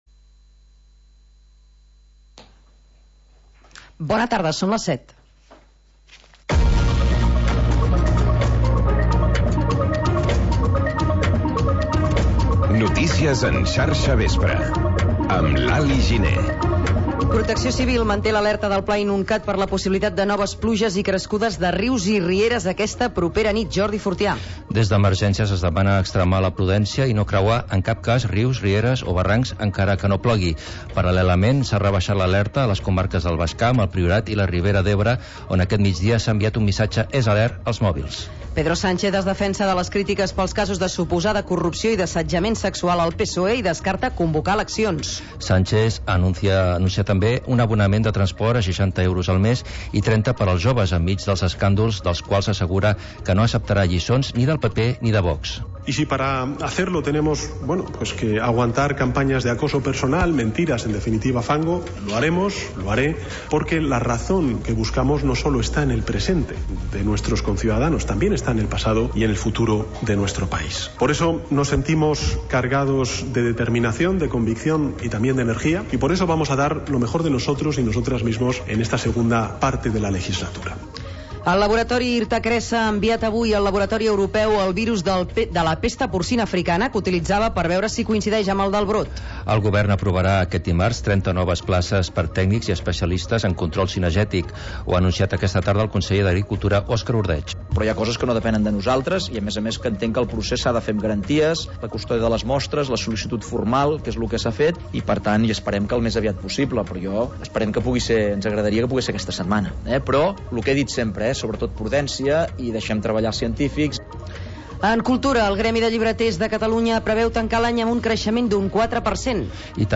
Informatiu que desplega una mirada àmplia sobre el territori, incorporant la informació de proximitat al relat de la jornada.